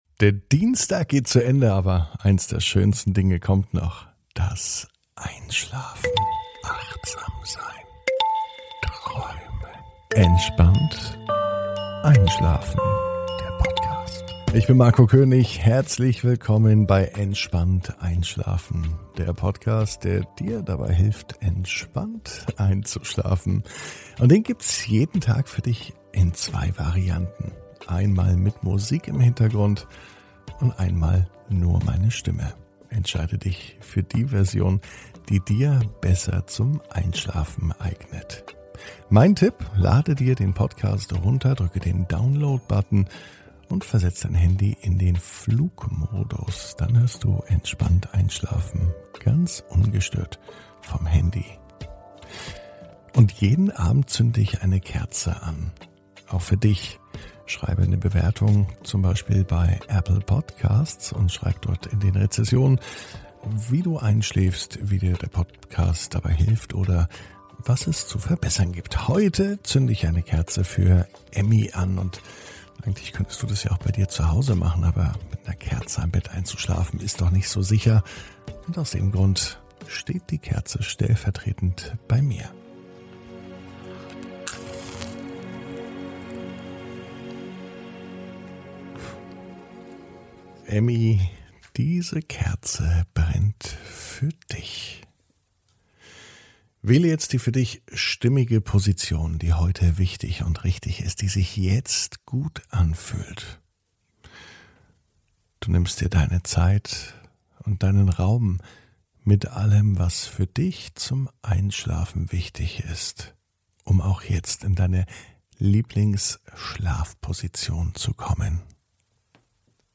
(ohne Musik) Entspannt einschlafen am Dienstag, 08.06.21 ~ Entspannt einschlafen - Meditation & Achtsamkeit für die Nacht Podcast